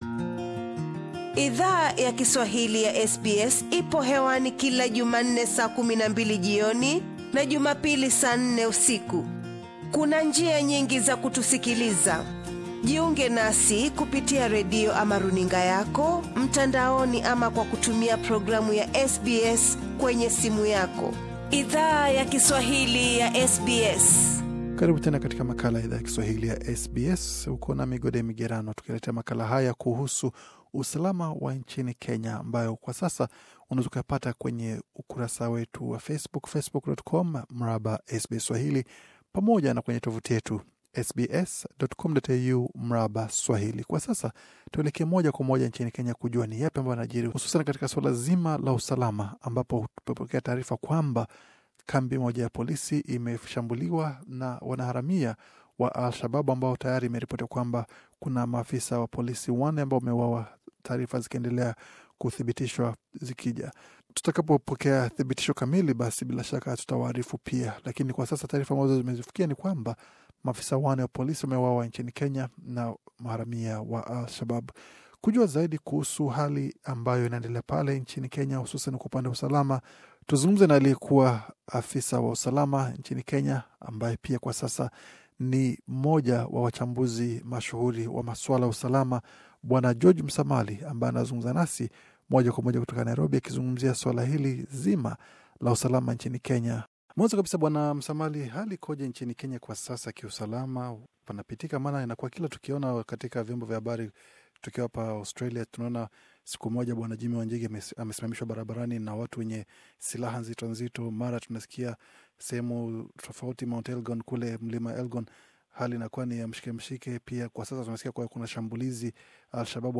Katika mazungumzo maalum na SBS Swahili, ali tuarifu kuhusu hali tete ya usalama nchini Kenya ambako mamlaka inakabiliana na vikundi tofauti nchini pamoja na kile cha Al Shabaab.